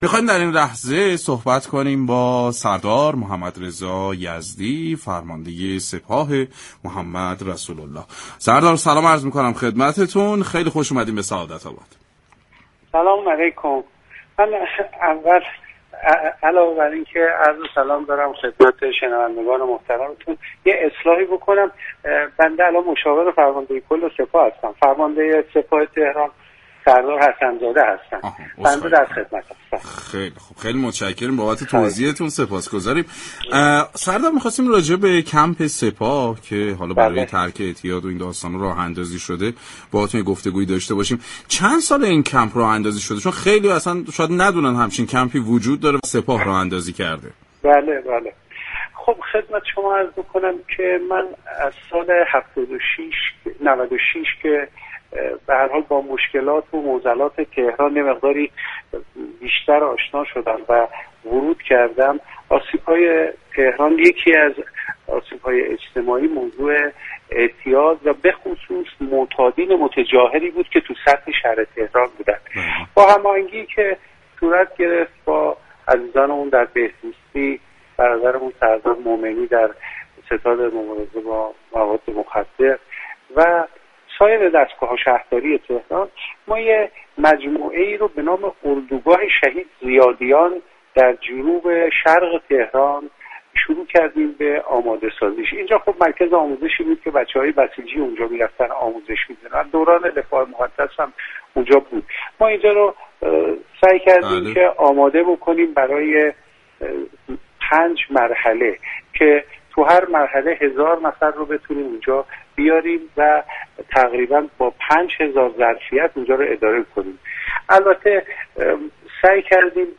به گزارش پایگاه اطلاع رسانی رادیو تهران، سردار محمدرضا یزدی مشاور فرمانده كل سپاه پاسداران در گفتگو با سعادت‌آباد رادیو تهران گفت: